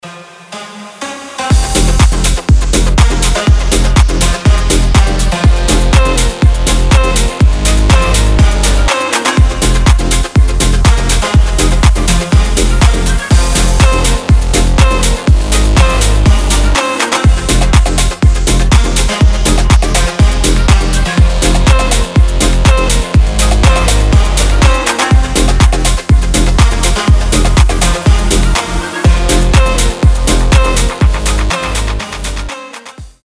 • Качество: 320, Stereo
громкие
атмосферные
без слов
басы
deep progressive
восточные
Восточный deep progressive house